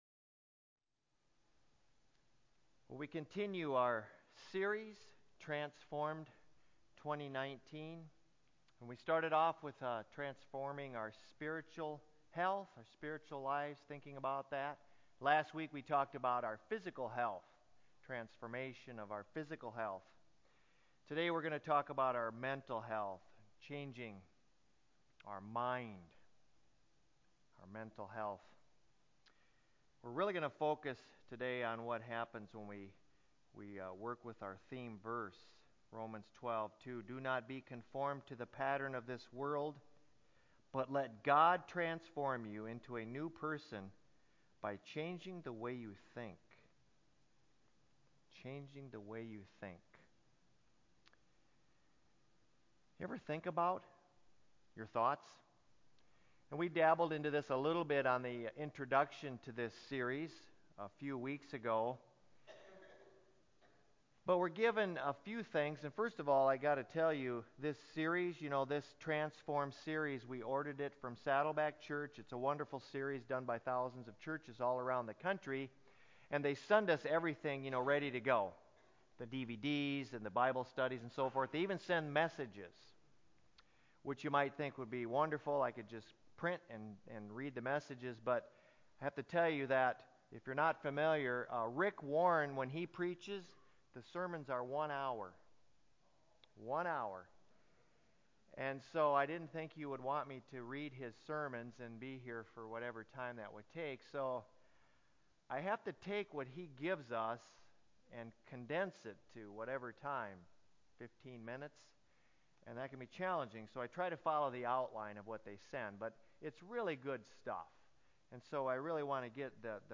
Church_Sermon_1.27-CD.mp3